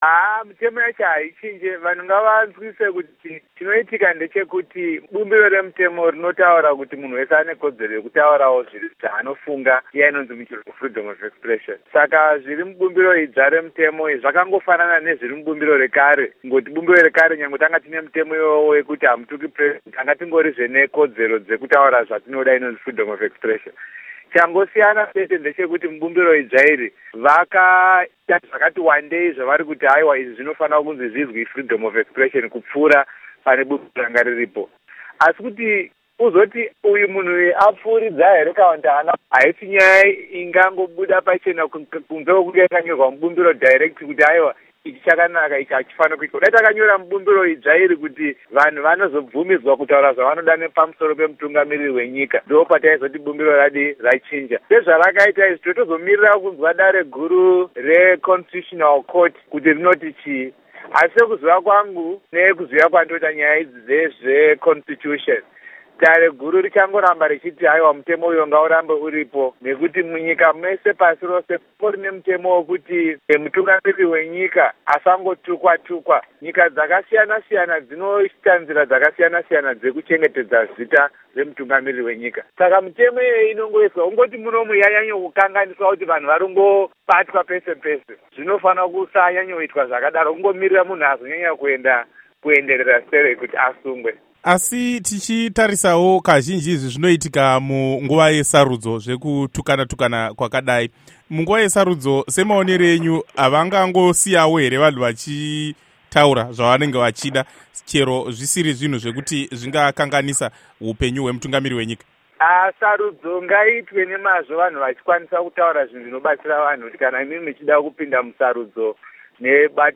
Hurukuro naVaLovemore Madhuku